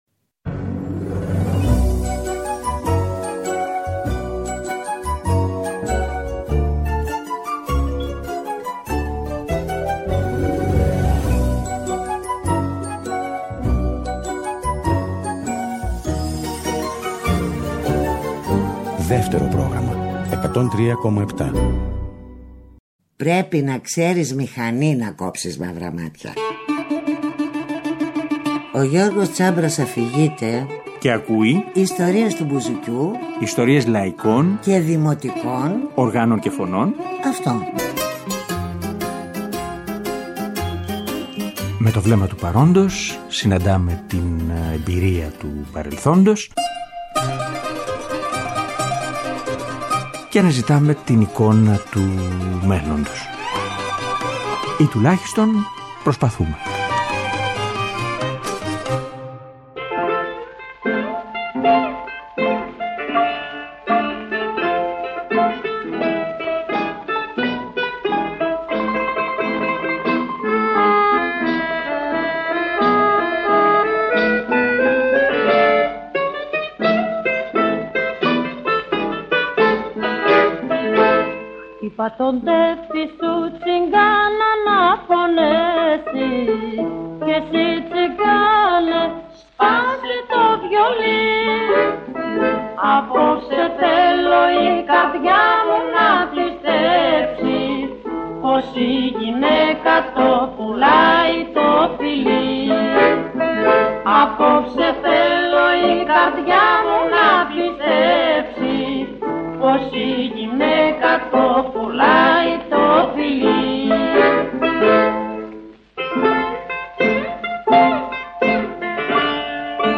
Κάποια τραγούδια πολυτραγουδισμένα μέσα απ’ τα χρόνια στις πρώτες τους εκτελέσεις.